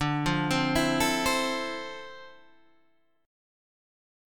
Dm9 Chord
Listen to Dm9 strummed